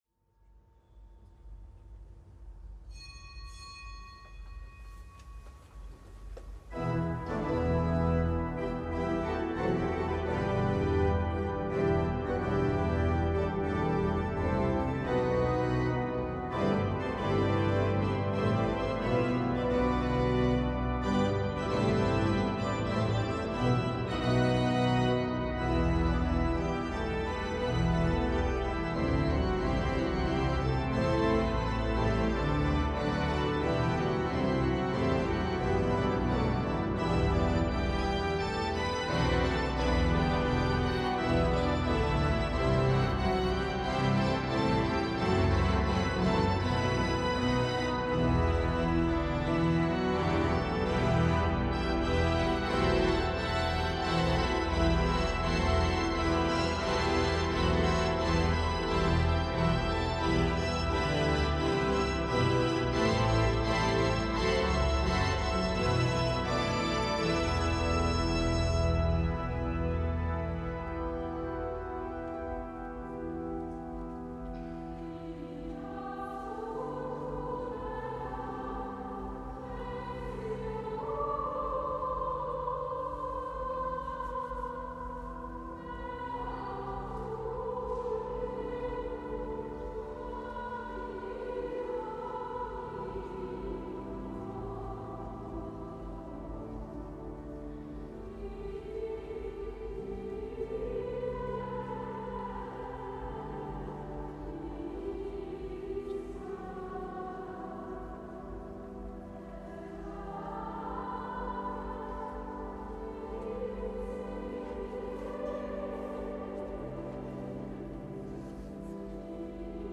Kapitelsamt am siebenunszwanzigsten Sonntag im Jahreskreis